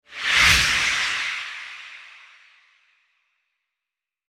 Ghost Swoosh Sound Effect Free Download
Ghost Swoosh